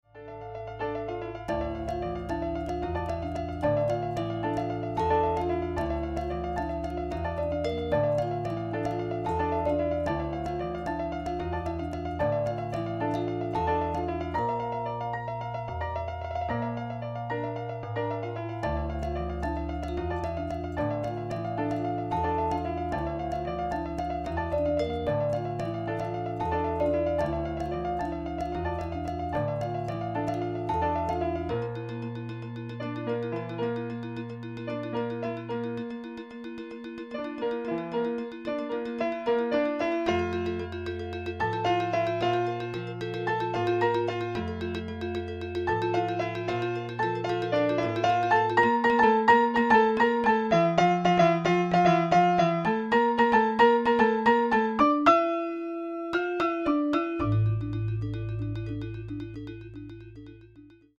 Digital piano, Javanese gender, and gong